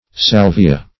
Salvia \Sal"vi*a\